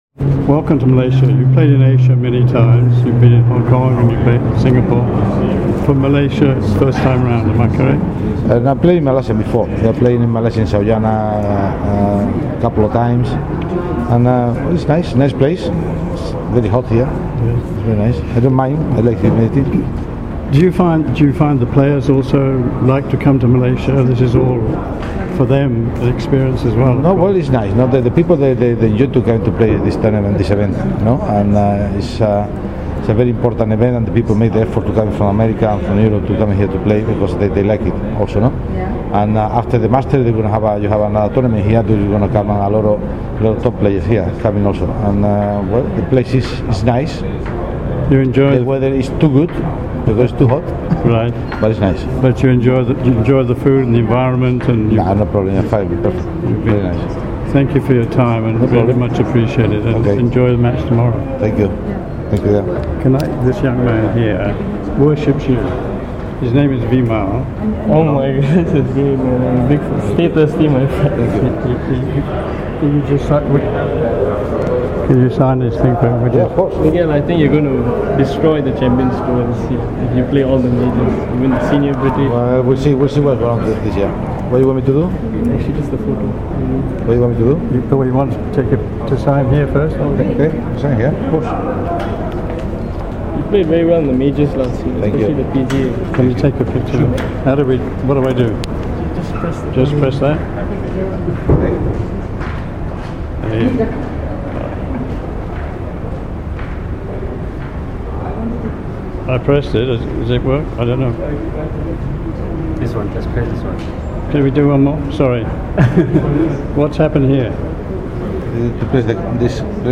MGTA interviews Miguel Angel Jimenez